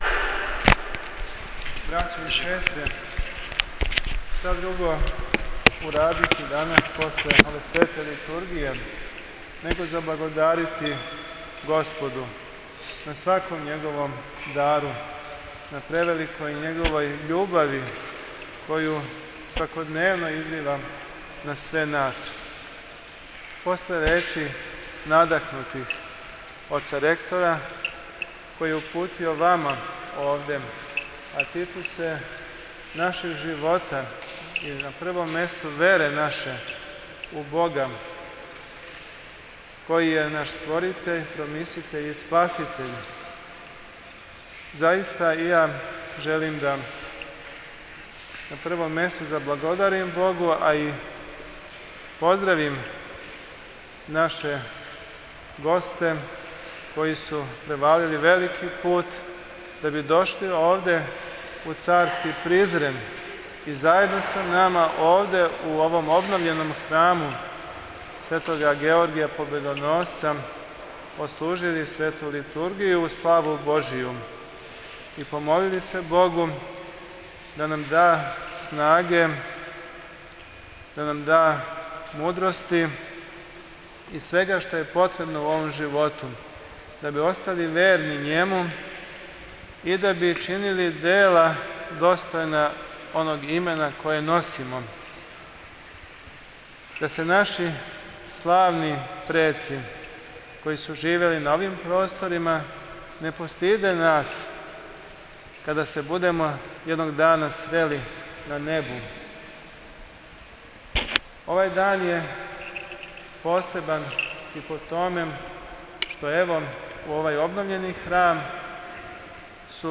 Беседа Епископа Теодосија